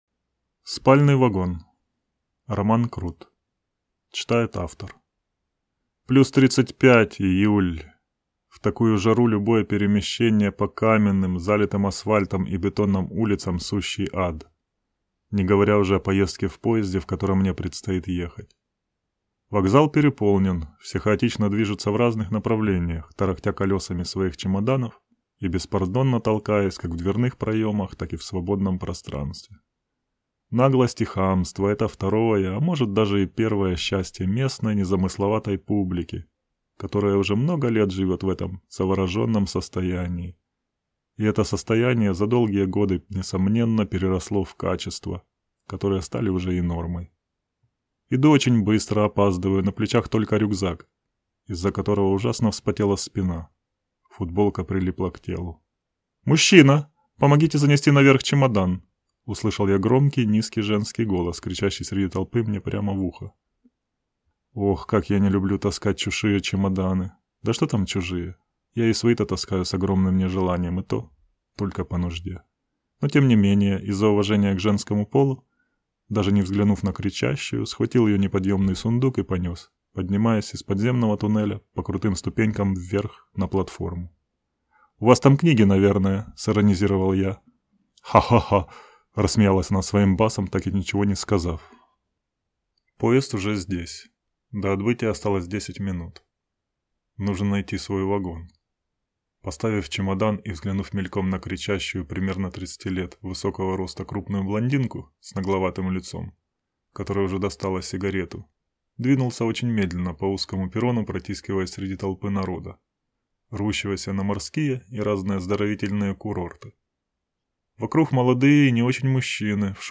Аудиокнига Спальный вагон | Библиотека аудиокниг